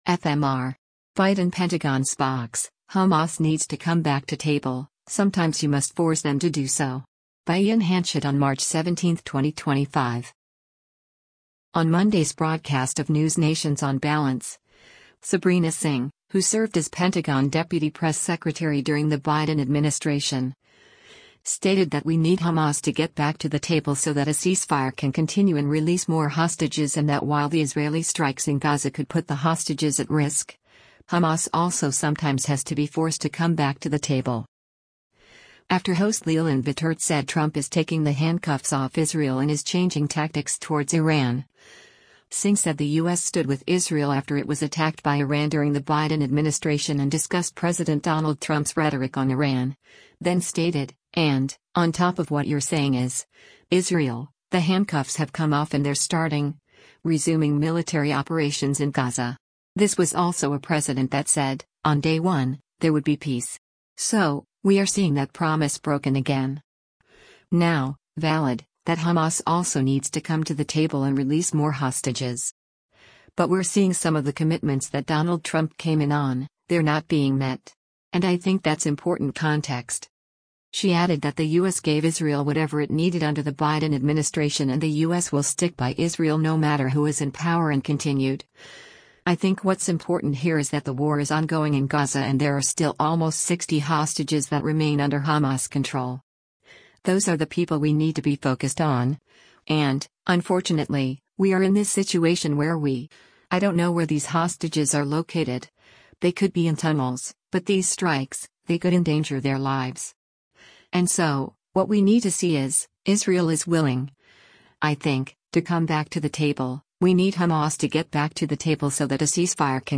On Monday’s broadcast of NewsNation’s “On Balance,” Sabrina Singh, who served as Pentagon Deputy Press Secretary during the Biden administration, stated that “we need Hamas to get back to the table so that a ceasefire can continue” and release more hostages and that while the Israeli strikes in Gaza could put the hostages at risk, Hamas also sometimes has to be forced to come back to the table.